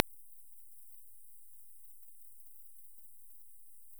Trollpipistrell
Pipistrellus nathusii     Pnat
Trollpipistrellens Emax ligger vanligen mellan 35 och 45 kHz.
Här är ett mer lågfrekvent exemplar: